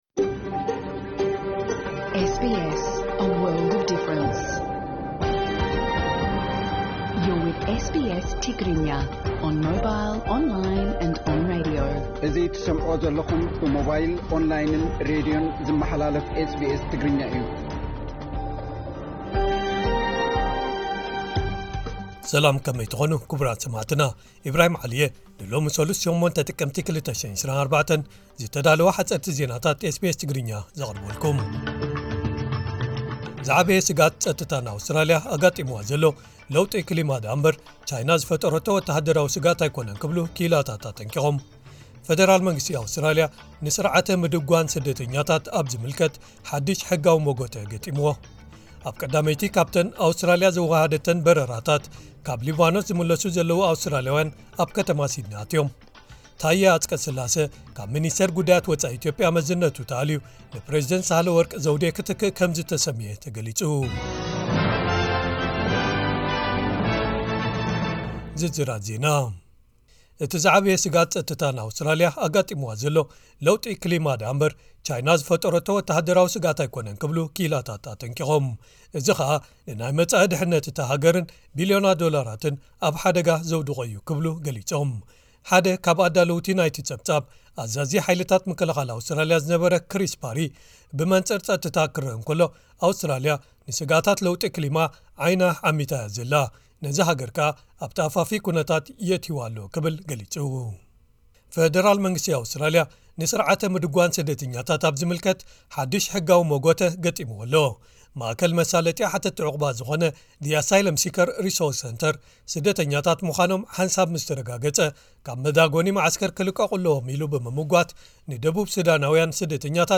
ሓጸርቲ ዜናታት ኤስቢኤስ ትግርኛ (08/10/2024)